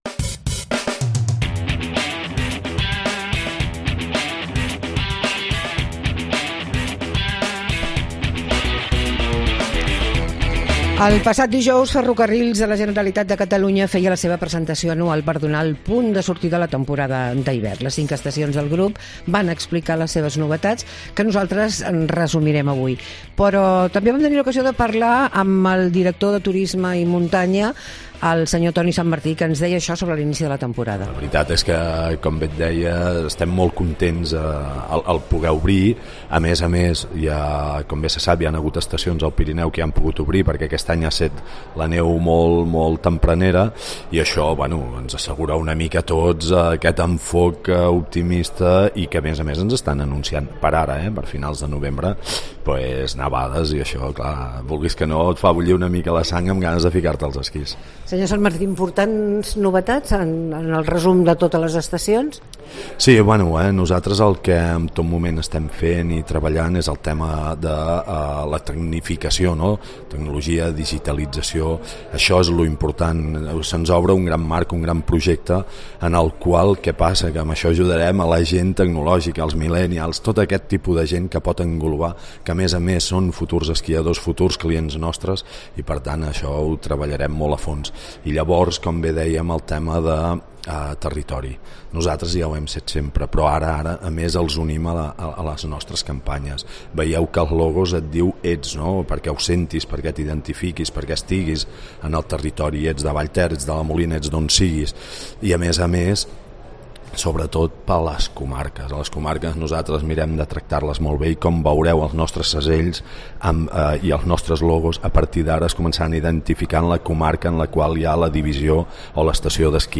Entrevistem